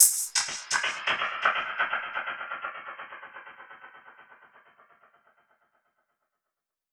Index of /musicradar/dub-percussion-samples/125bpm
DPFX_PercHit_E_125-08.wav